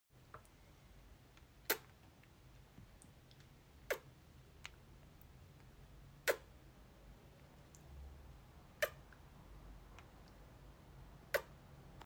Daily dose of kisses 💋 sound effects free download